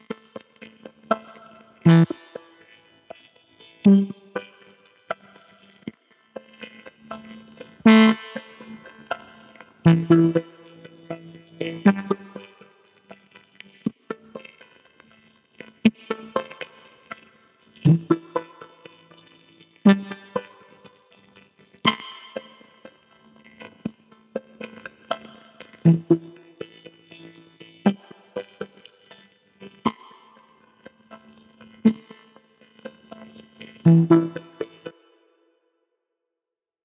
Problem: Ich erhalte immer ein sehr leises und stark verzerrte Spur (in datei angehängt) Das Audio lässt sich nur über MIDI und (external instrument) problemlos...